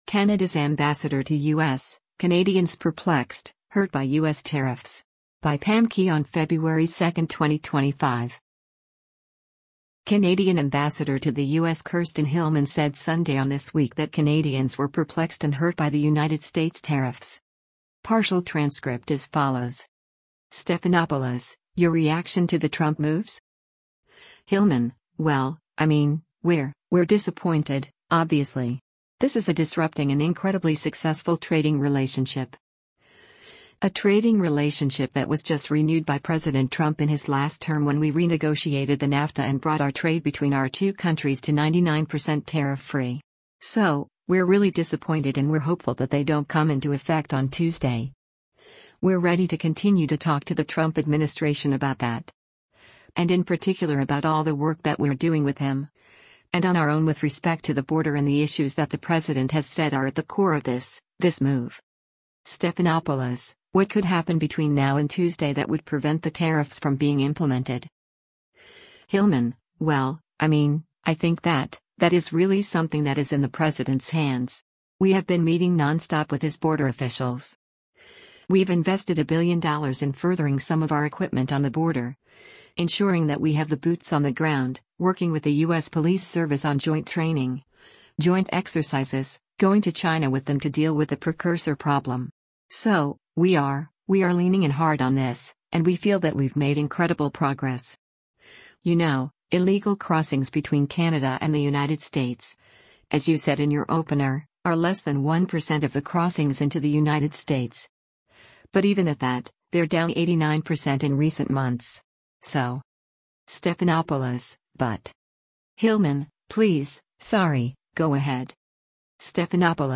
Canadian Ambassador to the U.S. Kirsten Hillman said Sunday on “This Week” that Canadians were “perplexed” and “hurt” by the United States’ tariffs.